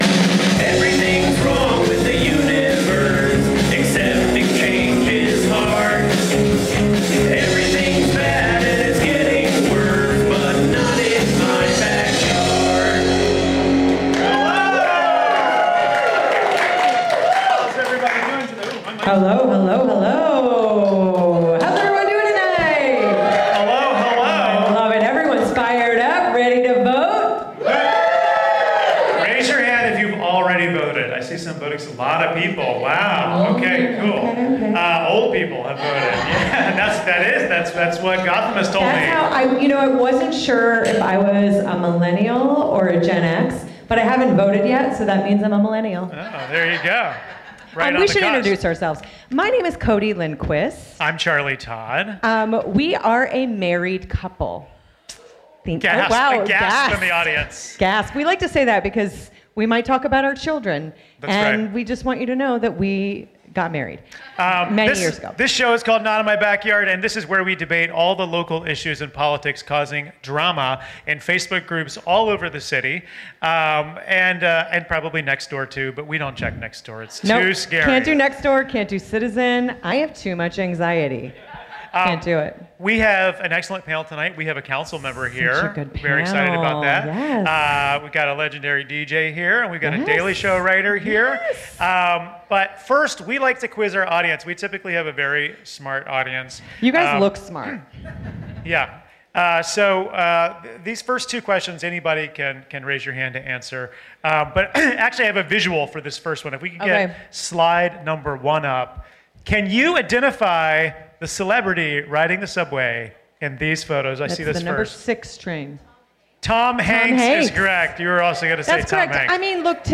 Live form the UCB Theatre NY, we discuss ballot proposals, the high cost of free parking, and how Zohran gets around the city.